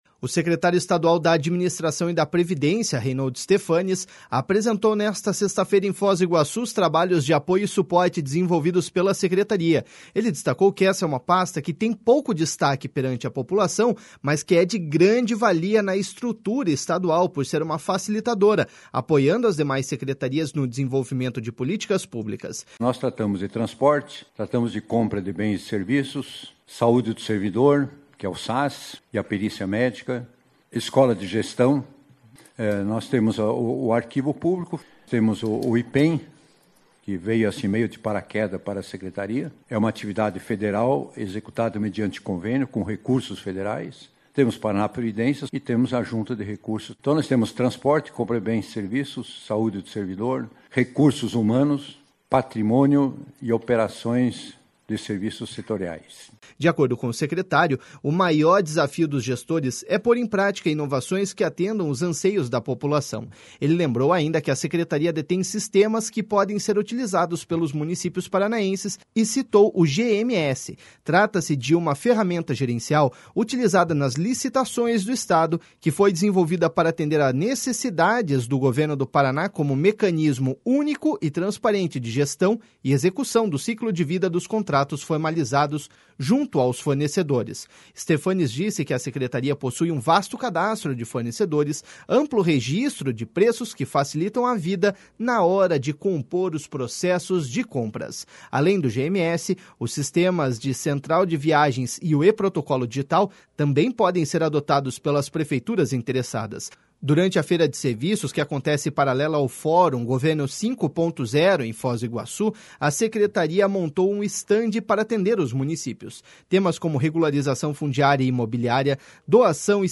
Ele destacou que esta é uma pasta que tem pouco destaque perante a população, mas que é de grande valia na estrutura estadual por ser uma facilitadora, apoiando as demais secretarias no desenvolvimento de políticas públicas.// SONORA REINHOLD STEPHANES.// De acordo com o secretário, o maior desafio dos gestores é pôr em prática inovações que atendam os anseios da população.